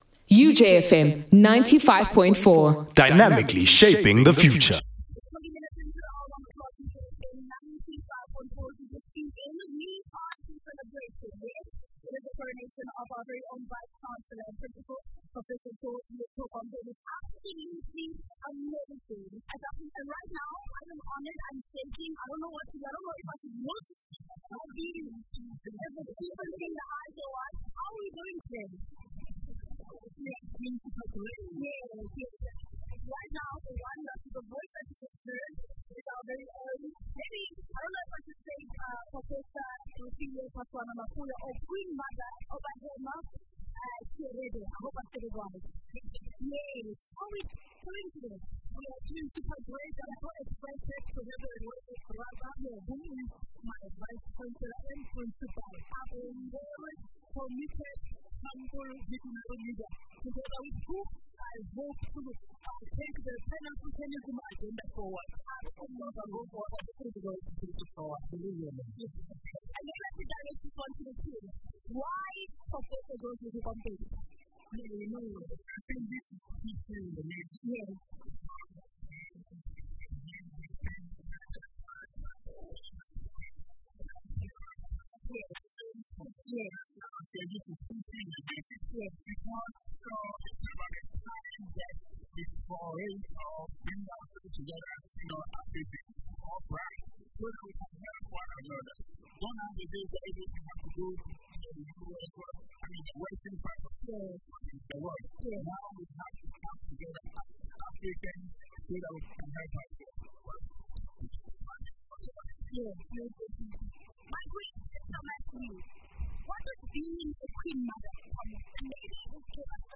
An interview with His Royal Majesty Daasebre Kwebu Ewusi VII